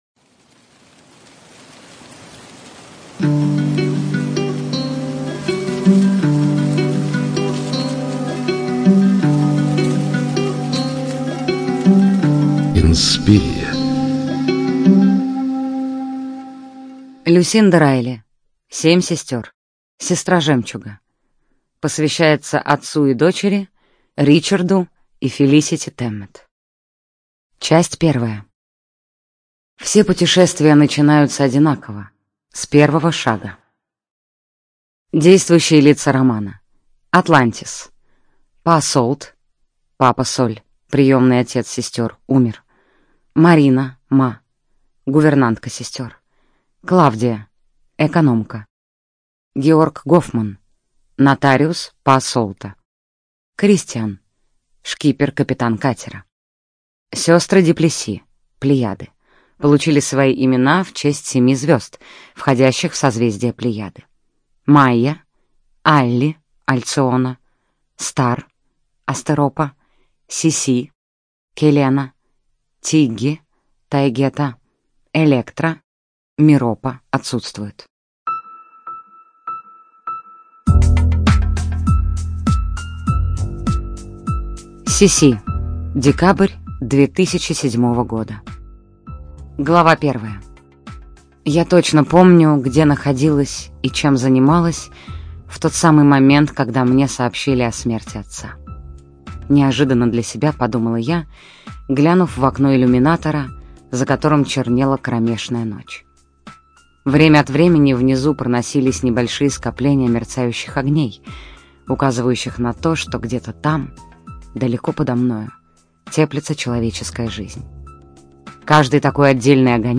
Студия звукозаписиInspiria